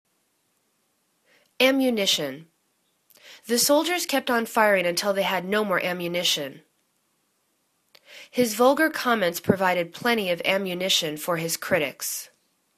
am.mu.ni.tion     /am'yu:nishən/    n